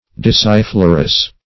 Search Result for " disciflorous" : The Collaborative International Dictionary of English v.0.48: Discifloral \Dis`ci*flo"ral\, Disciflorous \Dis`ci*flo"rous\, a. [See Disk , and Floral .]